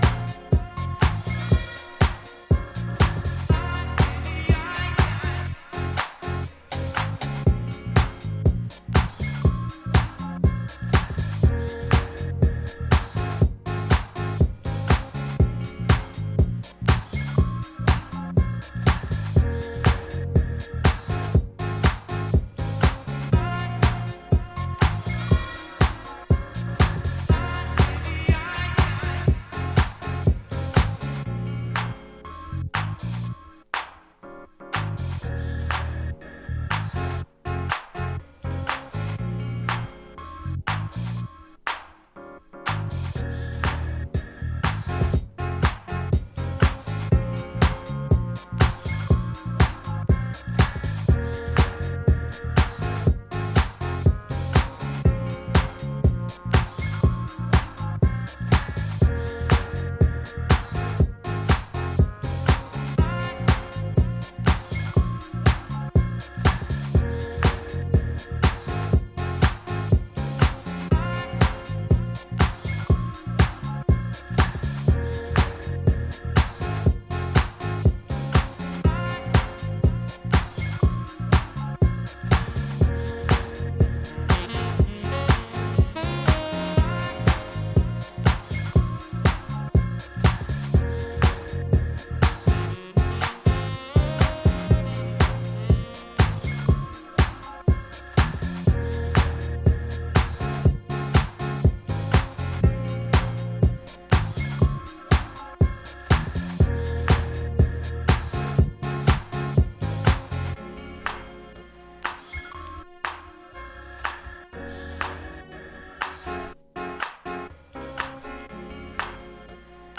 ジャンル(スタイル) DEEP HOUSE / DETROIT